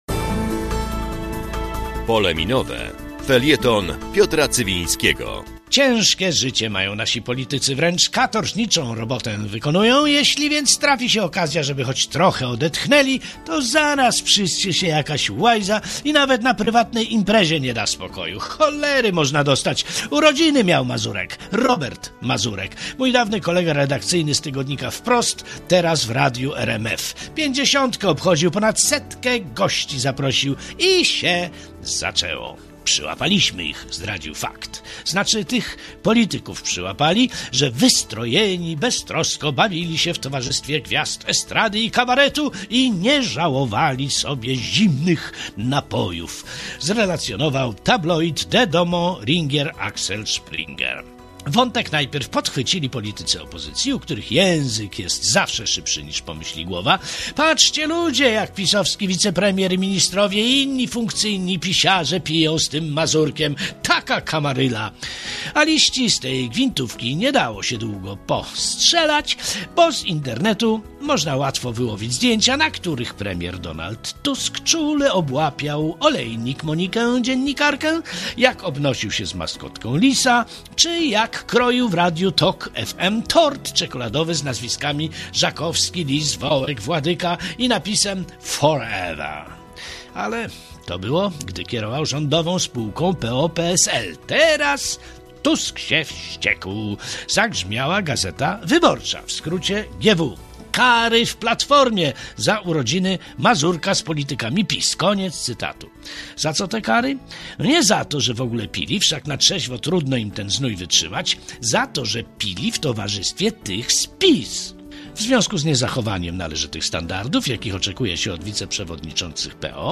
W Radiu Zachód w każdą sobotę po godz. 12:15.
"Pole Minowe" - felieton polityczny